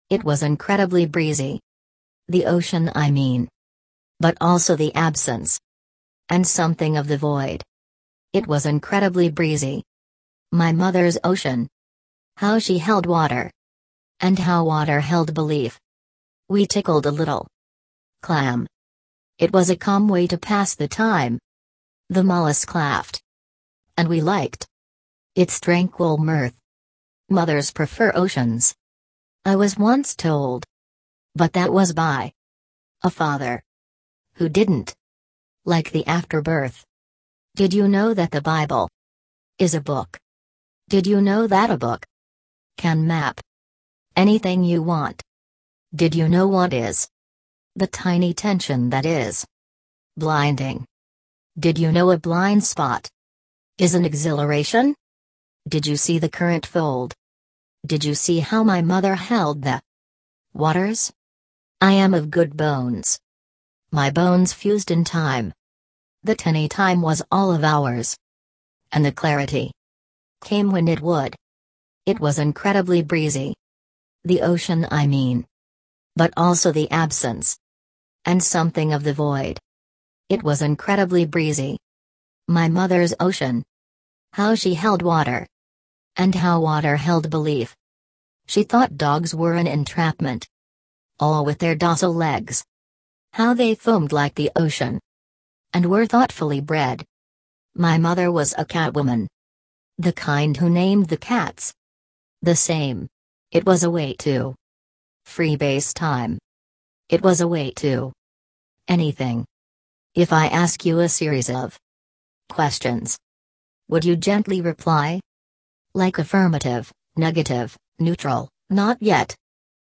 But, I liked the particular cadence of the robot in From Text To Speech. There are some words that the robot has to ‘use effort’ to pronounce. She can’t get all the words and I liked this idea that she is trying her best.